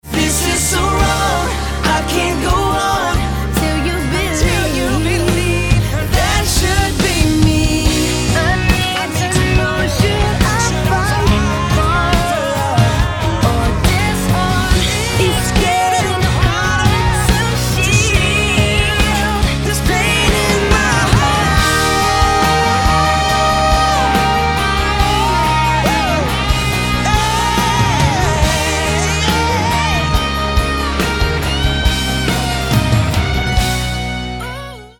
Ide o ďalšiu „teen pop“ pesničku